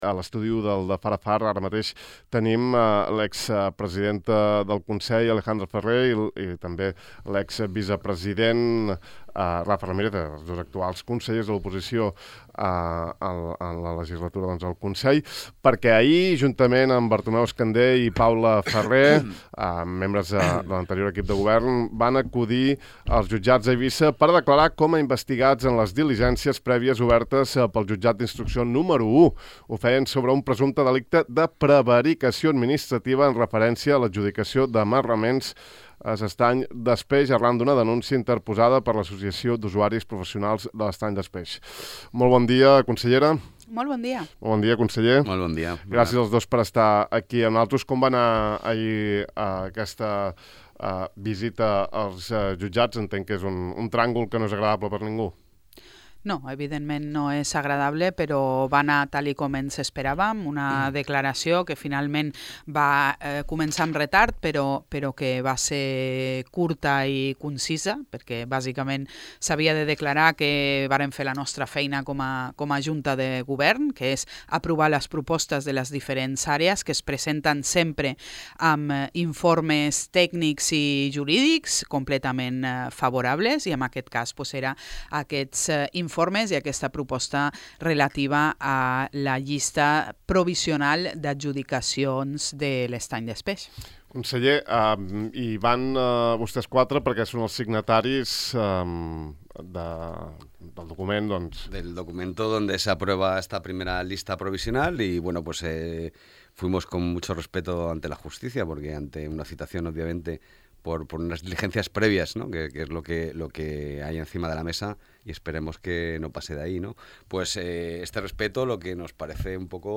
El divendres dia 4, tant Ferrer com Ramírez, respongueren a les preguntes de Ràdio Illa sobre aquesta investigació i compareixença, al programa De far a far. Afirmen que les seves actuacions a s’Estany des Peix sempre han set per garantir la protecció d’aquest espai natural protegit, i que la controvèrsia els va afectar electoralment de forma decisiva.
Podeu recuperar l’entrevista sencera clicant damunt d’aquest enllaç: